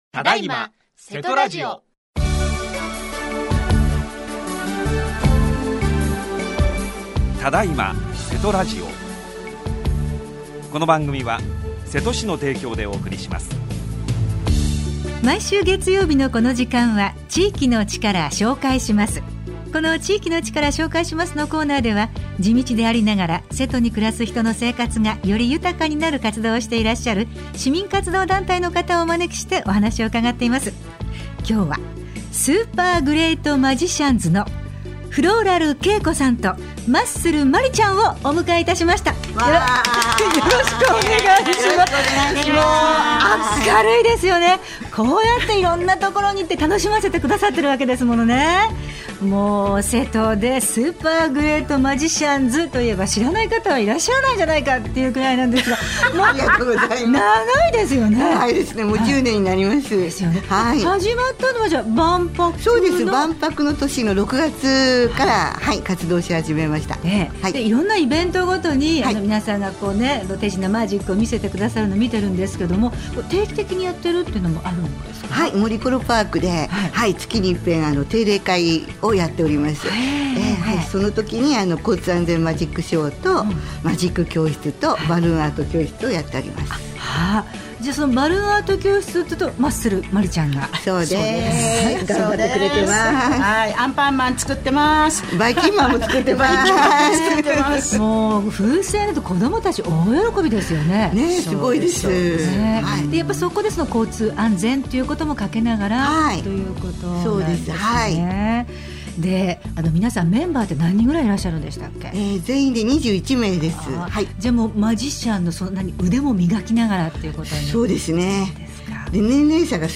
27年5月11日（月） 毎週月曜日のこの時間は、〝地域の力 紹介します〝 このコーナーでは、地道でありながら、 瀬戸に暮らす人の生活がより豊かになる活動をしていらっしゃる 市民活動団体の方をお招きしてお話を伺います。